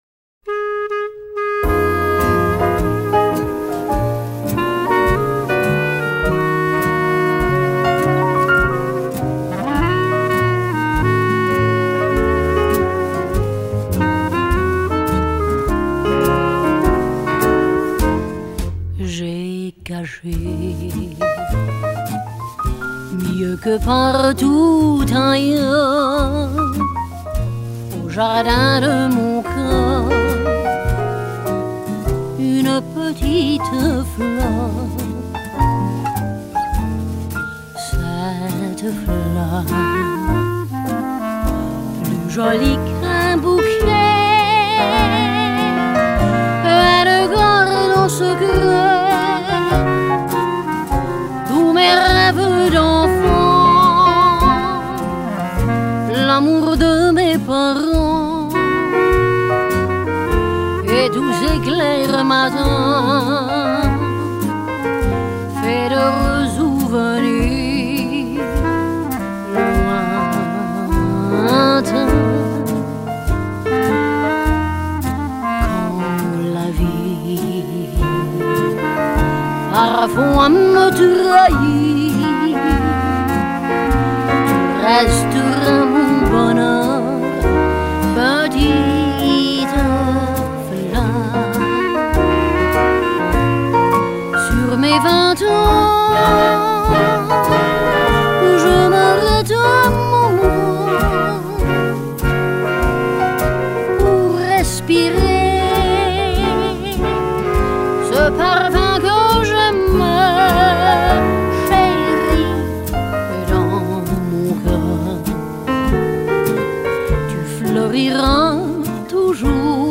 Жанр: Gypsy Jazz, Latin Jazz, Swing, Vocal Jazz